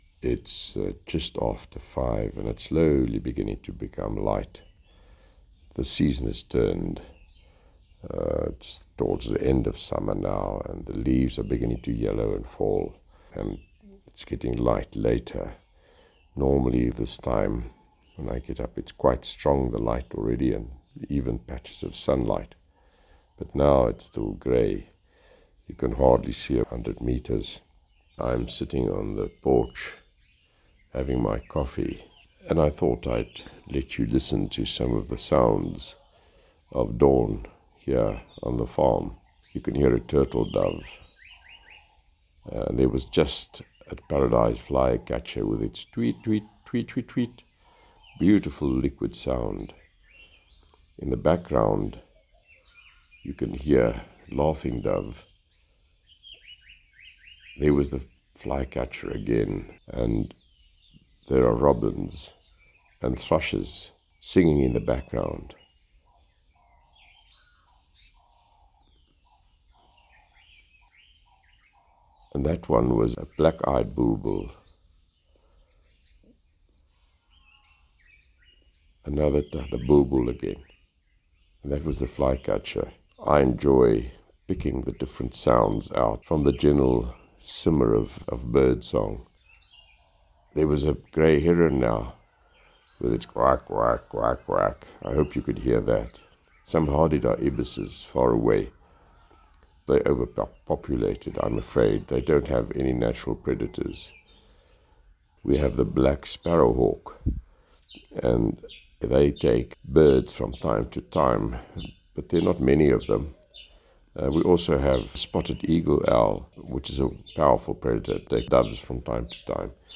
Morning Bird Sounds
Musings on early morning bird sounds and the day ahead.
Elandsfontein-morning-sounds.mp3